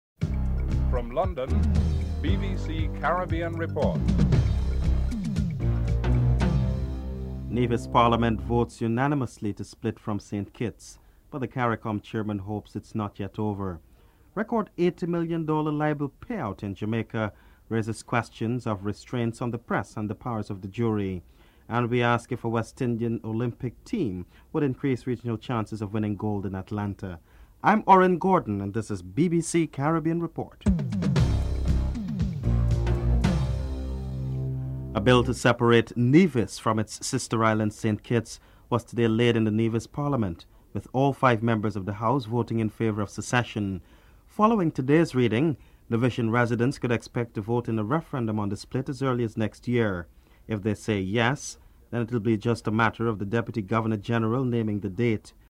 1. Headlines (00:00-00:34)
3. Eighty million dollars libel pay out in Jamaica raises questions of restraints on the press. QC Lord Gifford is interviewed (06:36-10:18)